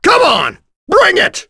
Gau-Vox_Victory_b.wav